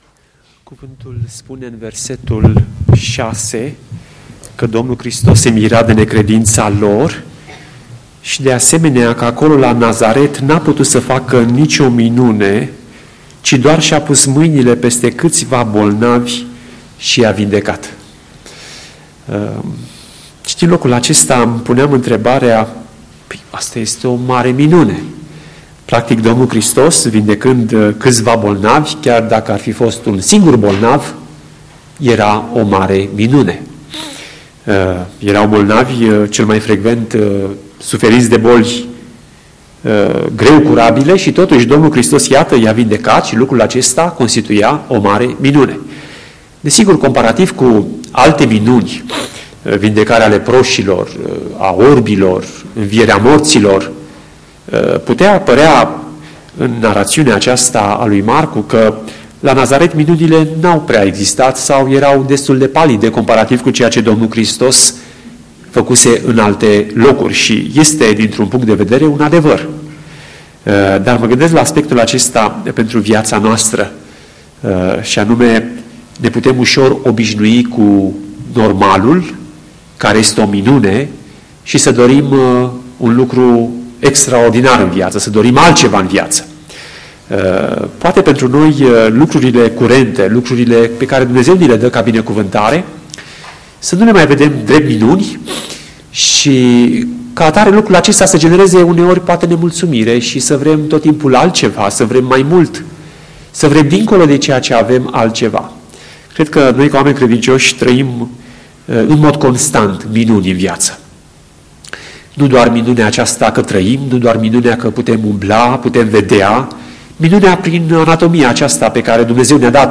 Predică